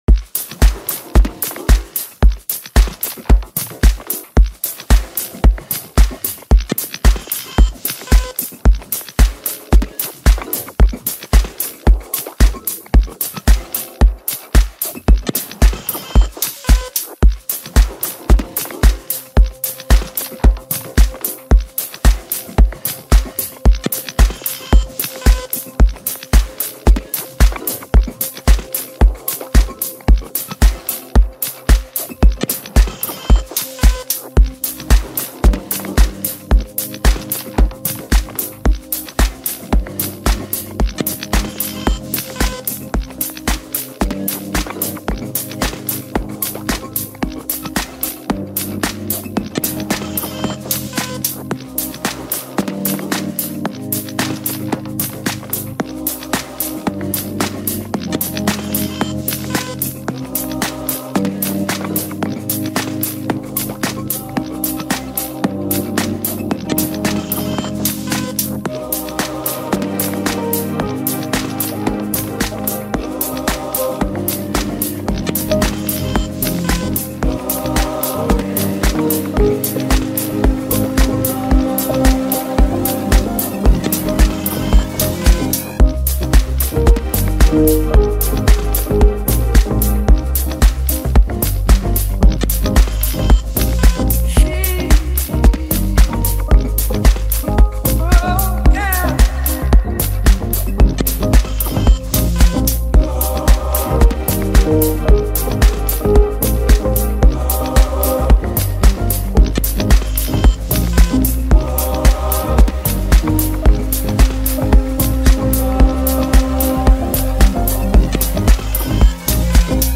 vibrant new song